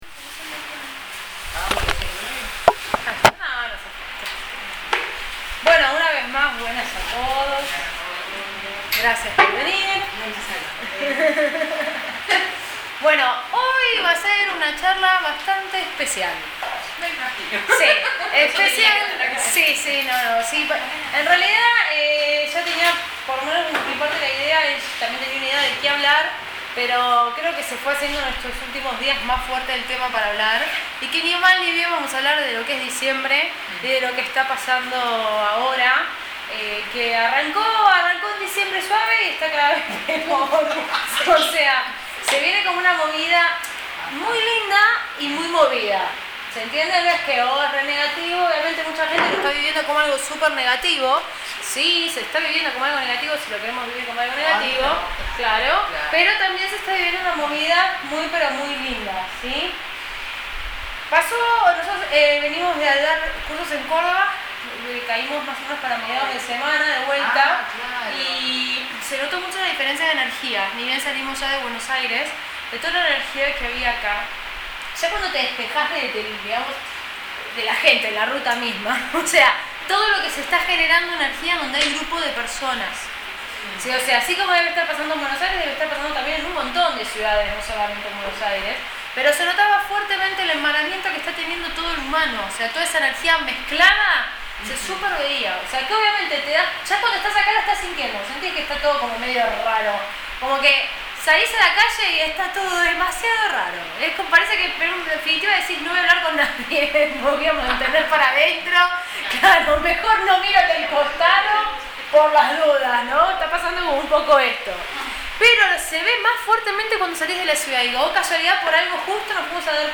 Meditación gratuita en Buenos Aires – Tema: Mensaje de los Guías para el mes de Diciembre 2012
En Capital Federal, Argentina.